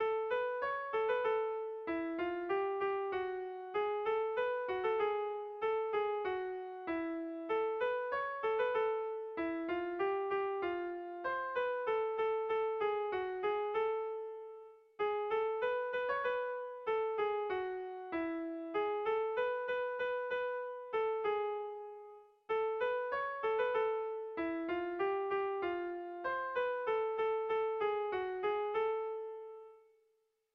Kontakizunezkoa
Zortziko handia (hg) / Lau puntuko handia (ip)
A1A2BA2